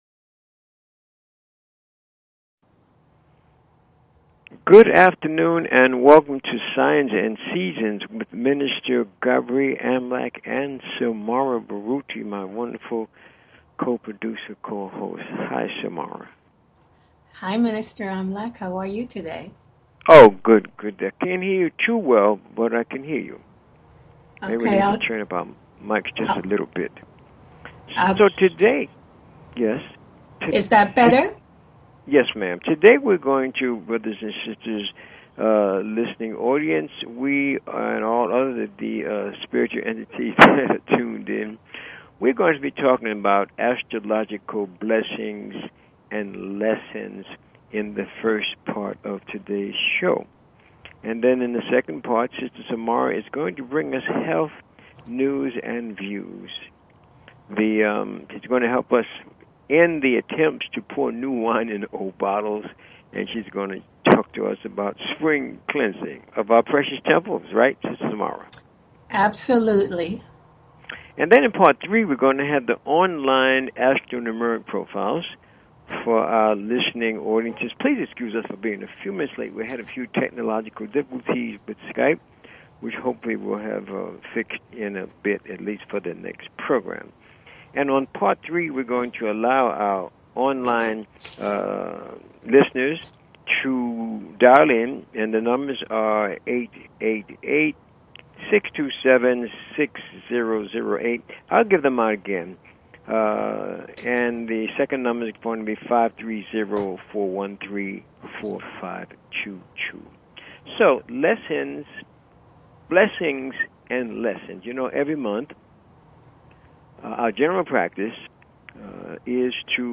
Talk Show Episode, Audio Podcast, Signs_and_Seasons and Courtesy of BBS Radio on , show guests , about , categorized as
The Primary objectives of this broadcast are: To ELIMINATE the fearful and superstitious attitudes that many peple have towards Astrology and related "Metaphysical" teachings by providing information on the constructive uses of these ancient sciences. And to demonstrate the practical value of Astrology and Numerology by giving on air callers FREE mini readings of their personal Astro-numerica energy profiles.